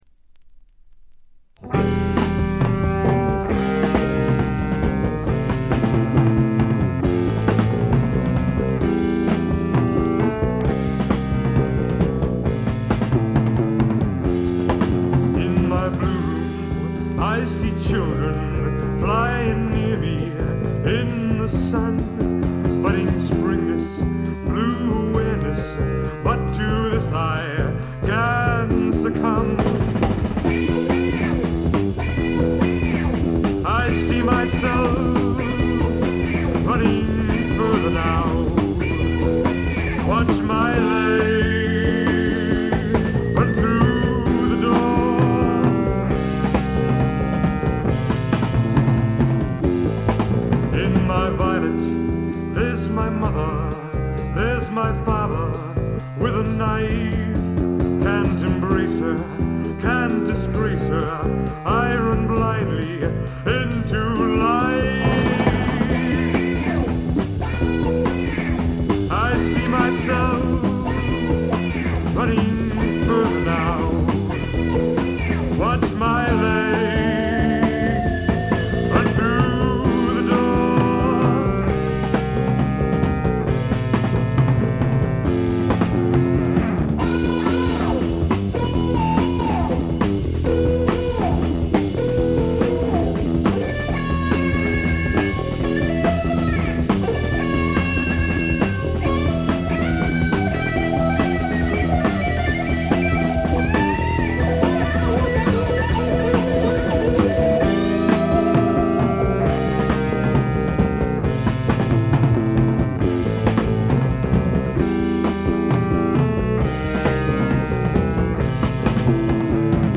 バックグラウンドノイズ、チリプチ多め大きめ。
マサチューセッツの5人組で男女ヴォーカルをフィーチャーしたジェファーソン・エアプレイン・タイプのバンドです。
試聴曲は現品からの取り込み音源です。